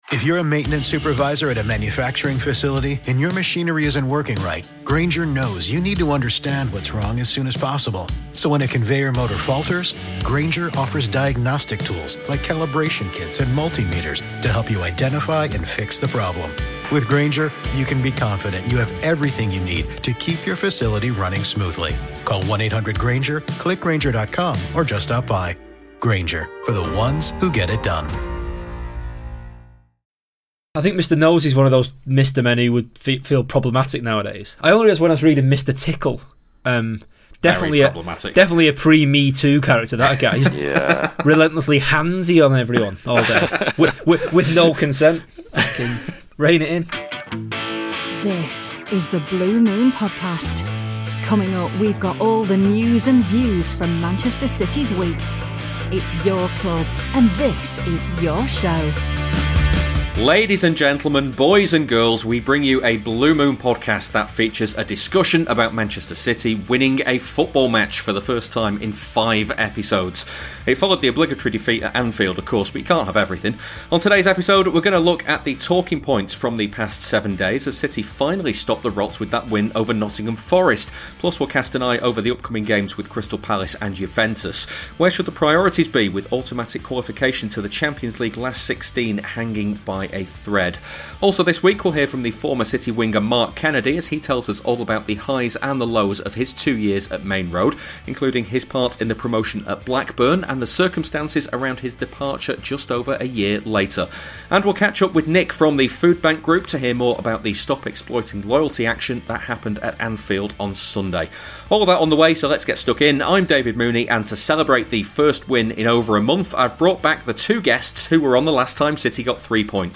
A first win since October, Grealish in the middle, Ortega as number one, plus an exclusive interview with ex-City winger Mark Kennedy